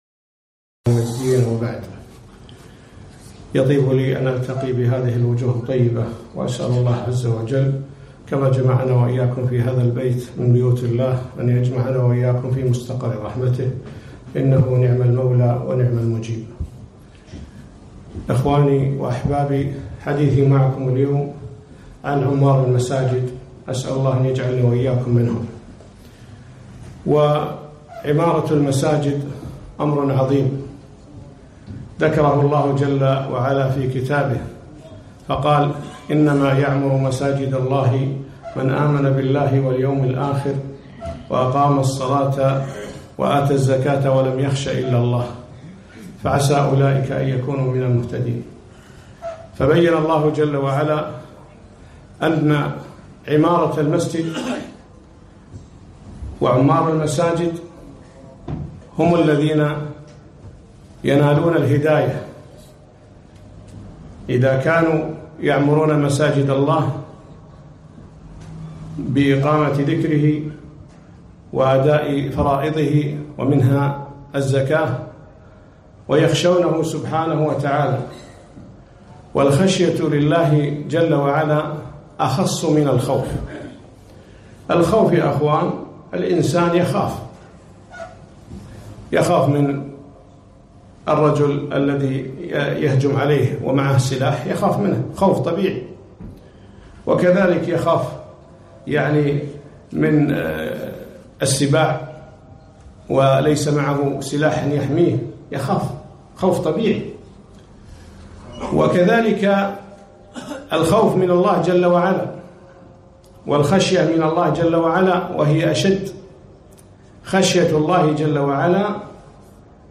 محاضرة - عمار المساجد